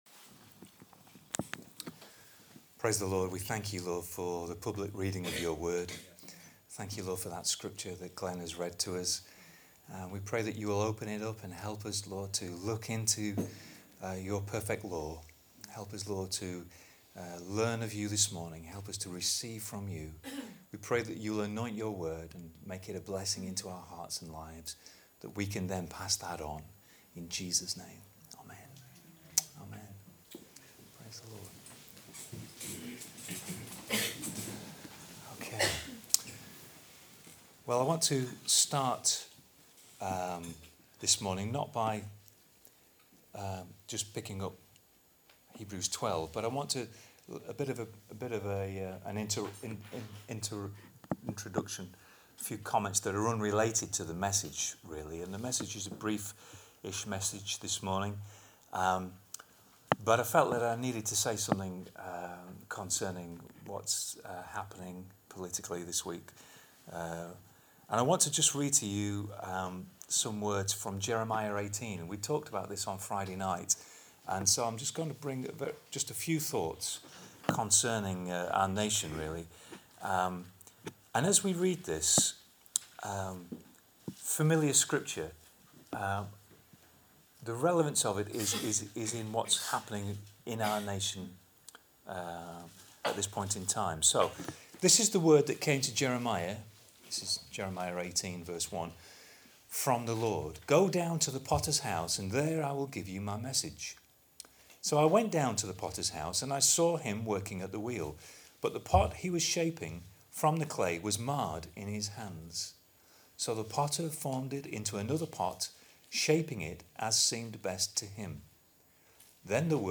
Listen to this message based on Hebrews 12:1-2 here: